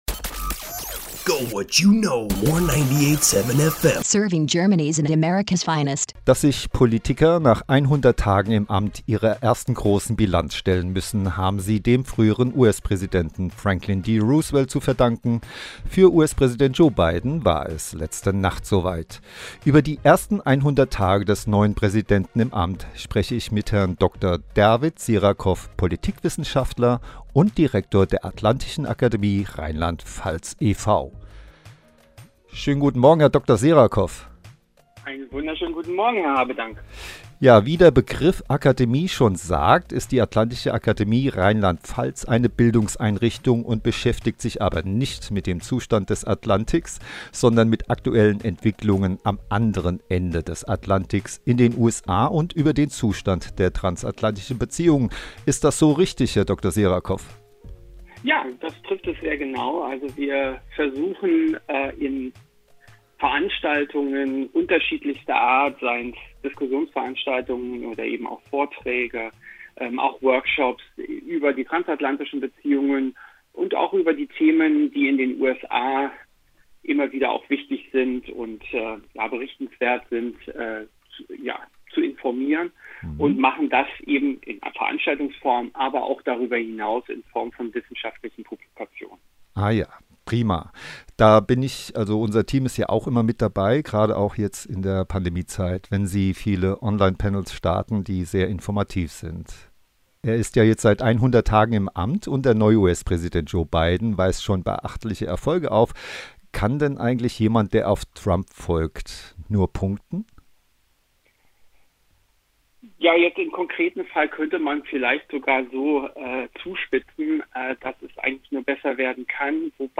Interview zu 100 Tagen Joe Biden